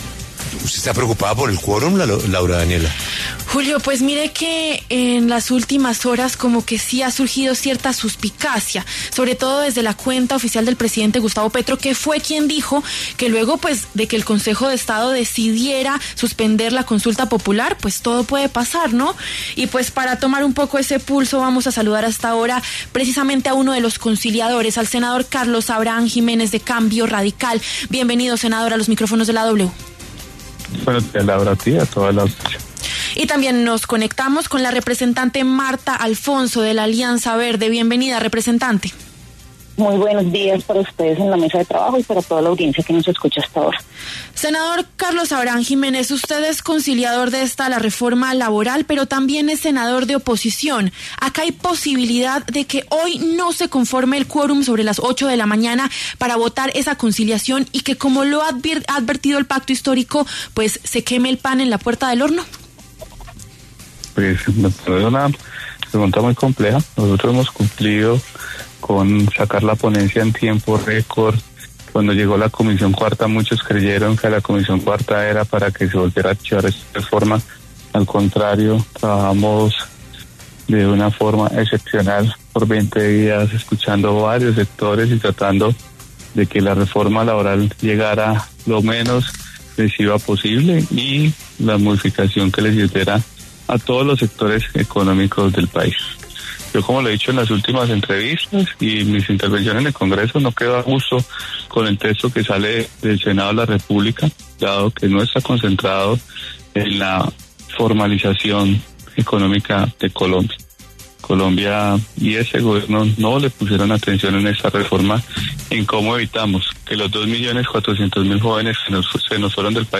El senador Carlos Abraham Jiménez, de Cambio Radical, y la representante Martha Alfonso, de Alianza Verde, pasaron por los micrófonos de La W.